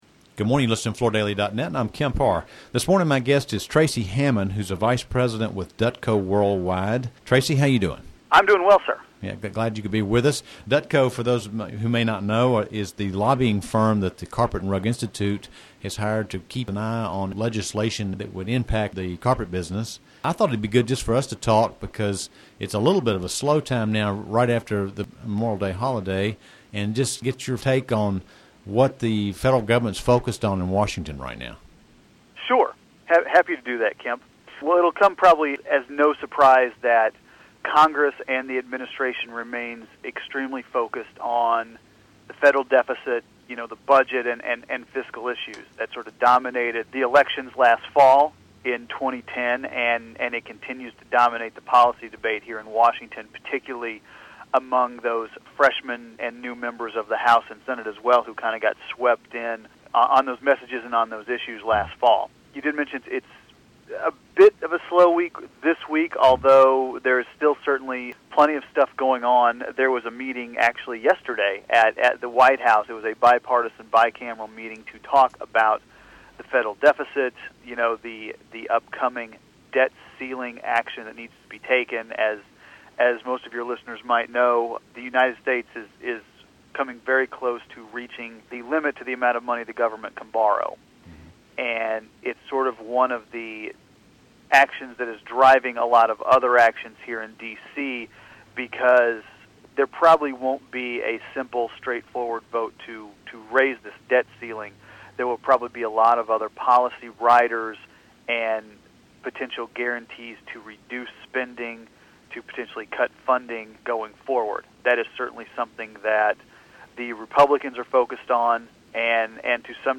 Listen to the interview to hear an insider's perspective on when we might start seeing any positive legislative movement that will allow our economy to start to move forward.